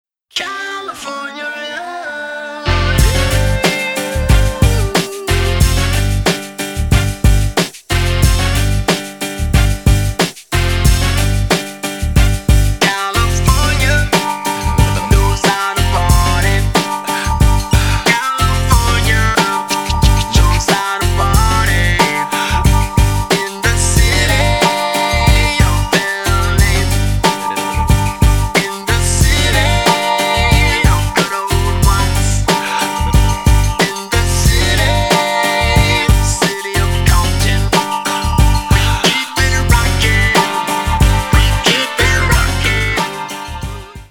• Качество: 256, Stereo
old school